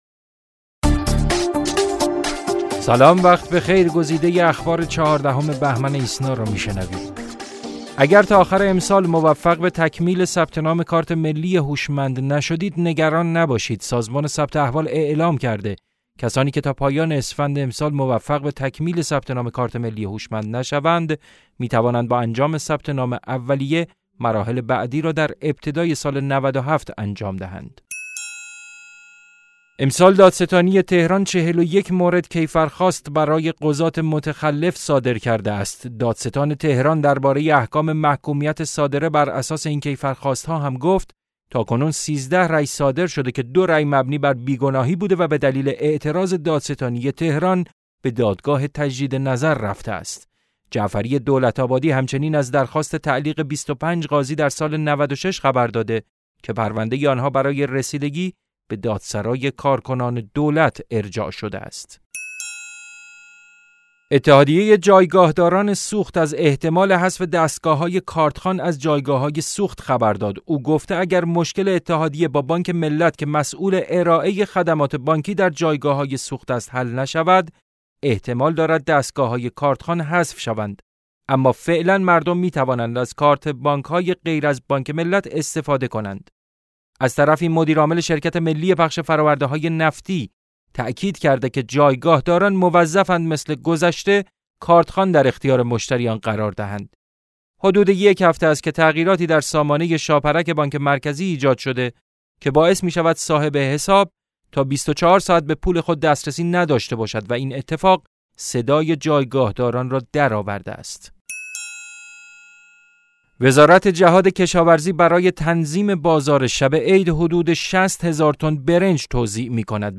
صوت / بسته خبری ۱۴ بهمن ۹۶